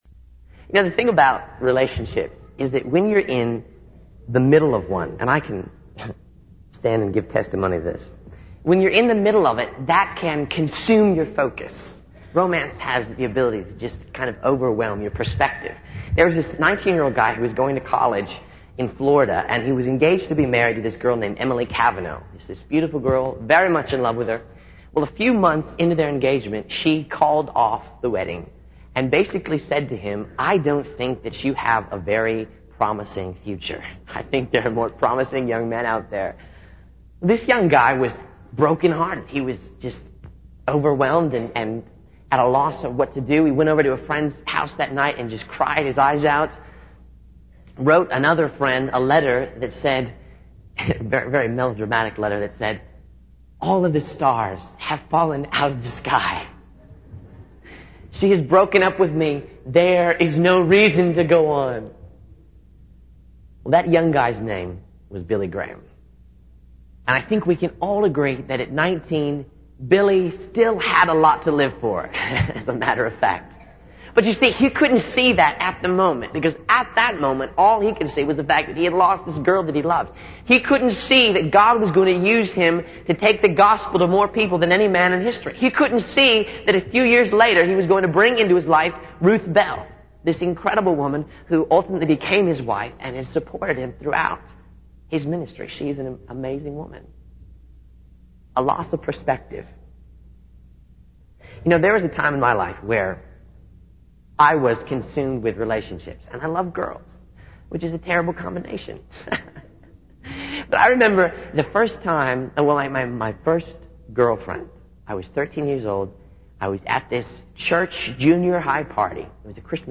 In this sermon, the speaker discusses the importance of living differently in order to avoid negative consequences in relationships.